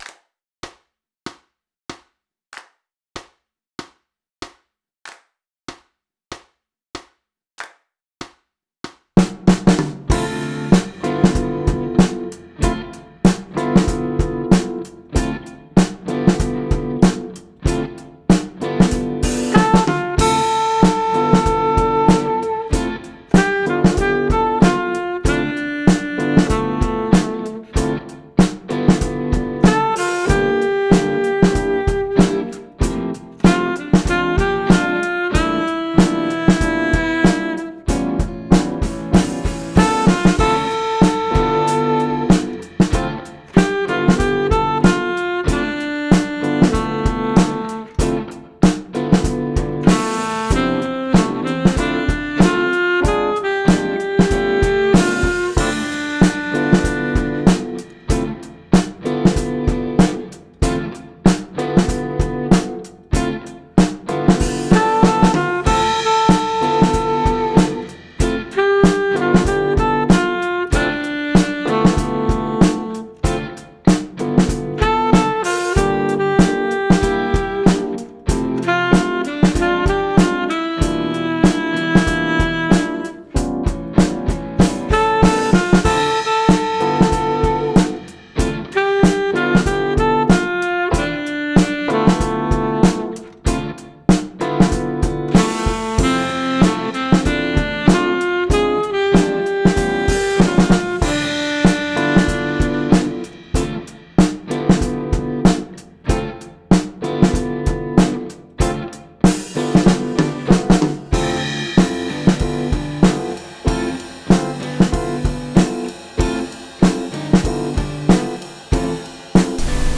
BANDCOACH BERLIN | Playalongs | Summertime Band
Summertime-ohne-Bass.wav